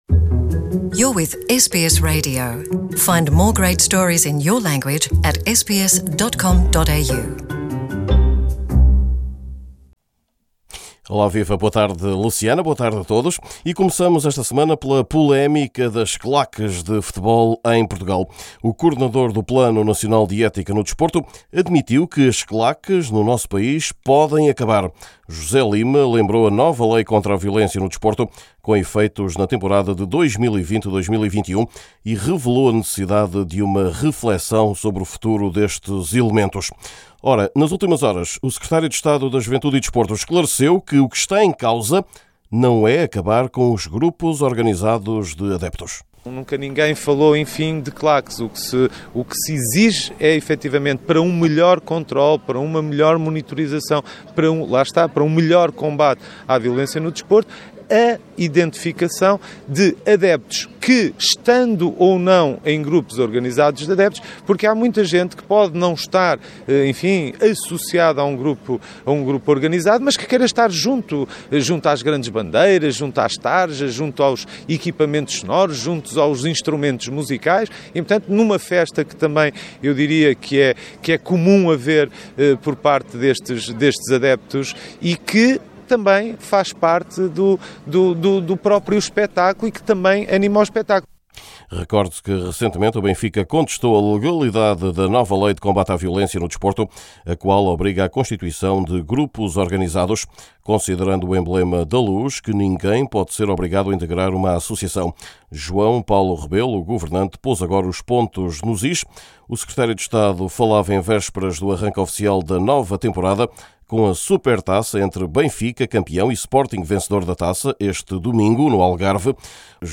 Também neste boletim esportivo a decisão da justiça em levar o antigo presidente do Sporting a julgamento.